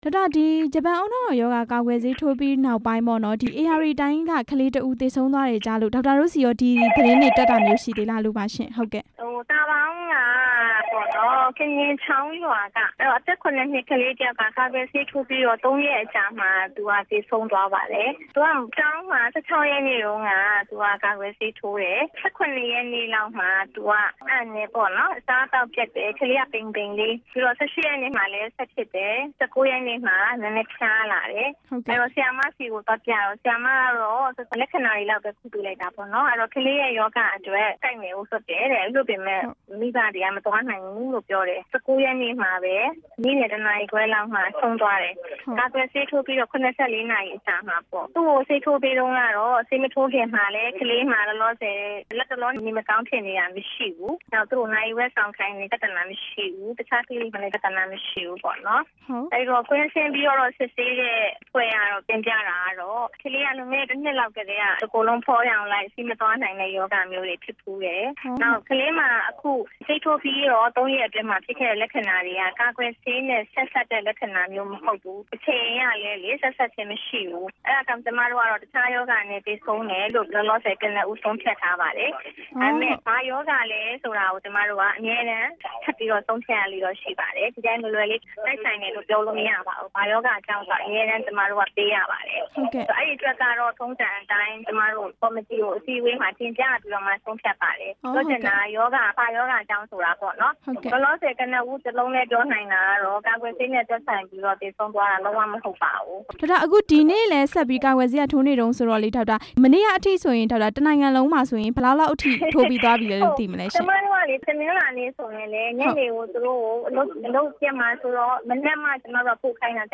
ဂျပန်ဦးနှောက်ရောင် ကာကွယ်ဆေးတွေ ထိုးတဲ့အကြောင်း မေးမြန်းချက်